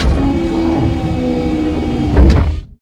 cargonetlower.ogg